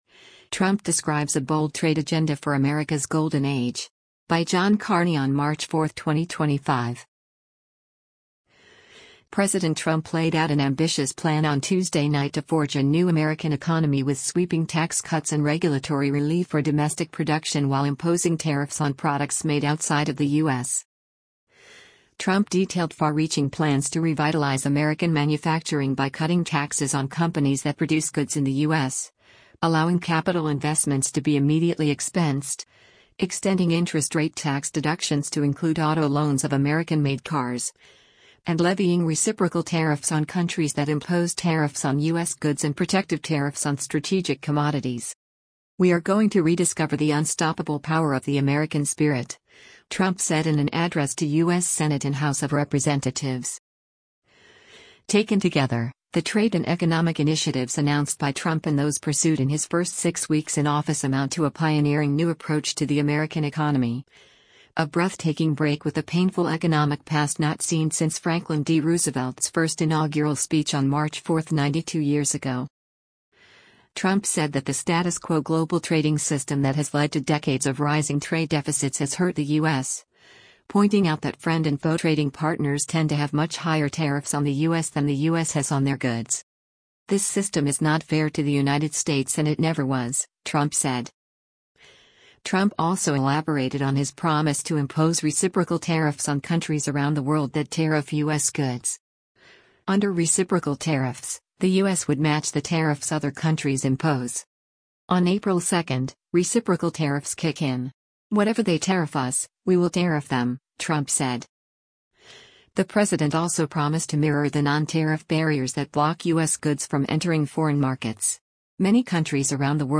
President Donald Trump talks with reporters before boarding Marine One on the South Lawn o